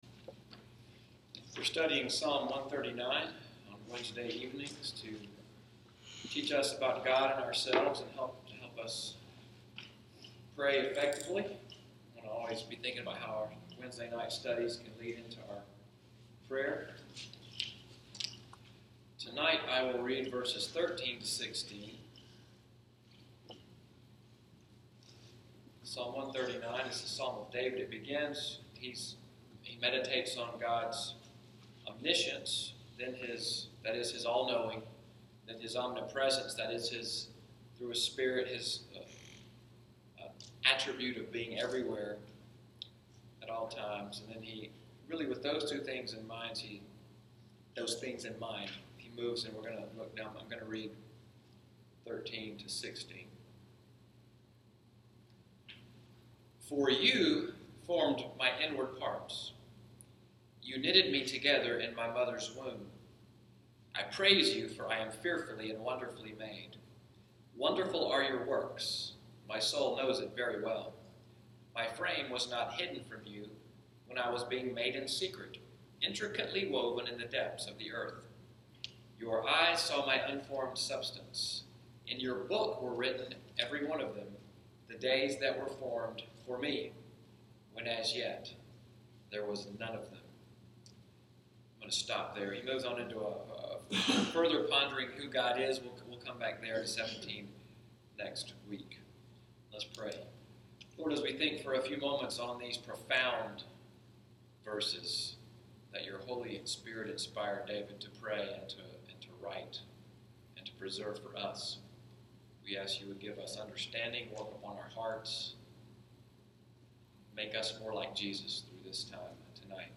Wednesday Evening Bible Study at NCPC, “Psalm 139:13-16 Bible study,” August 10, 2016.